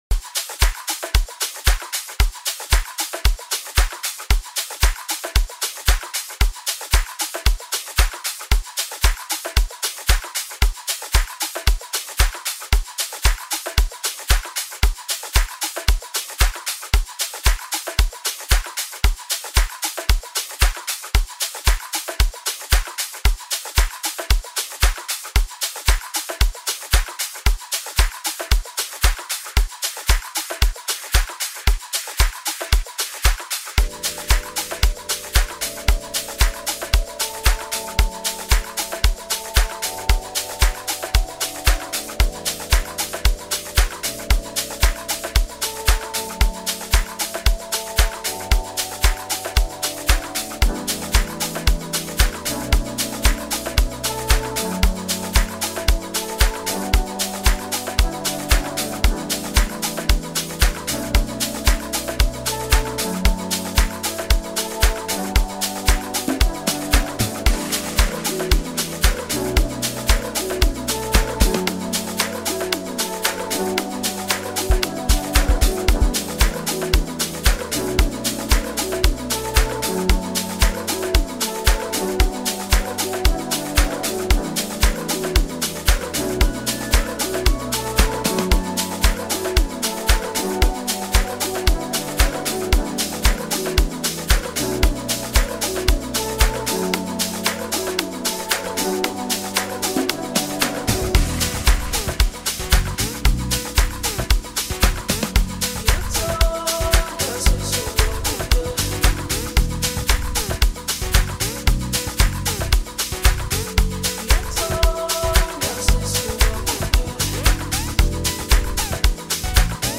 Amapiano